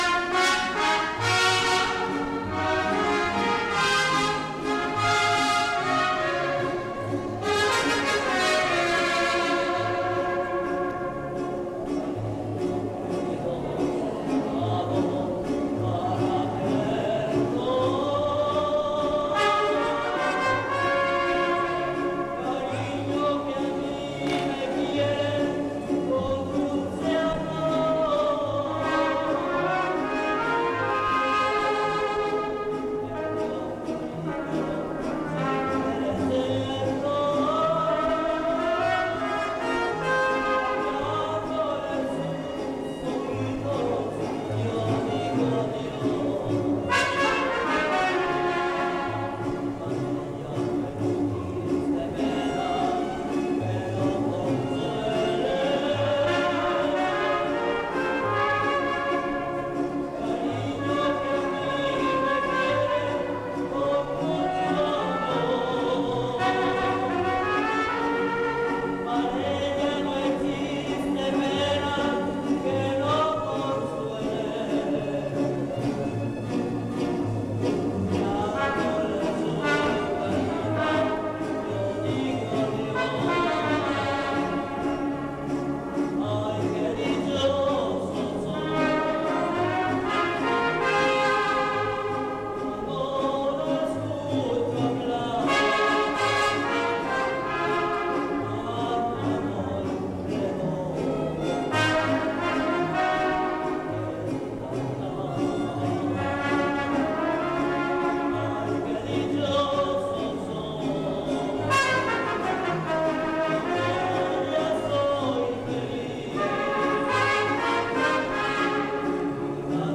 Fiesta del Señor Santiago